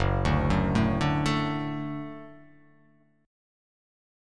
Gbm#5 Chord